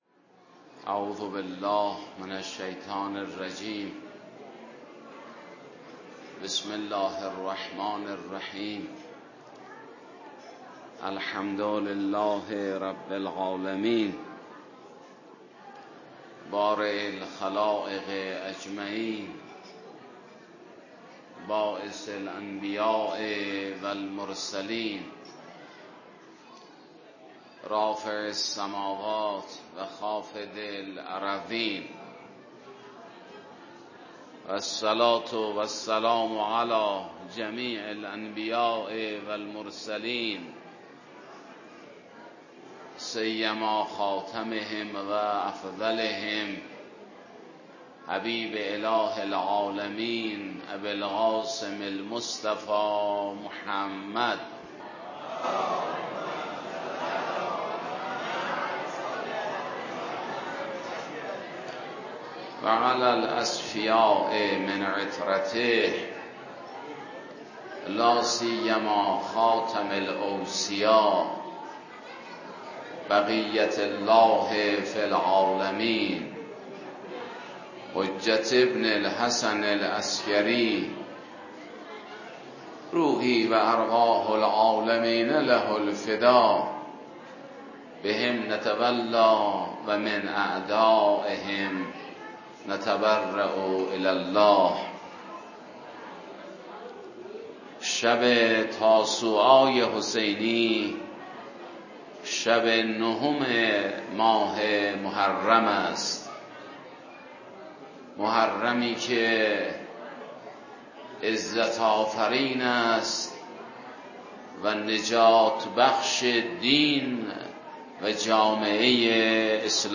شب تاسوعای حسینی، در جمع عزاداران حسینی به ایراد سخنرانی پرداخت